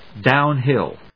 音節dówn・híll 発音記号・読み方
/ˌdaʊnˈhɪl(米国英語)/